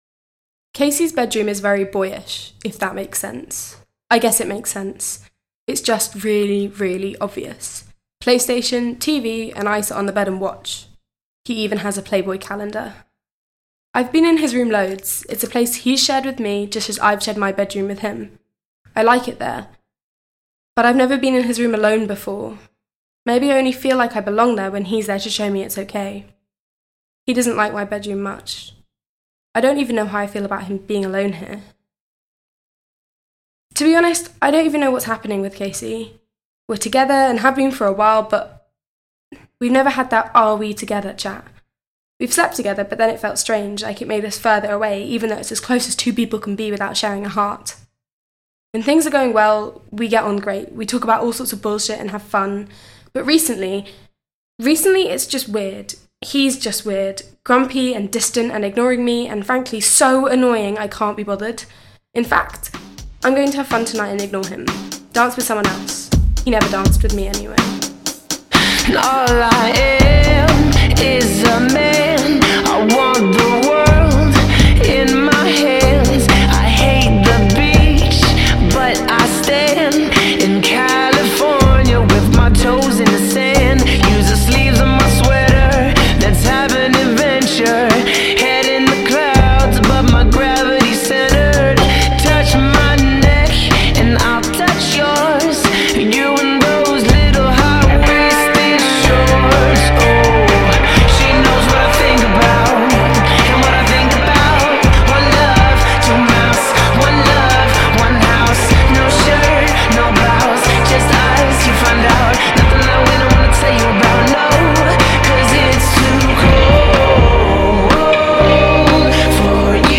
Hear Reece's monologue from Bred by Tricycle Young Company as part of Takeover 2016: Paradise.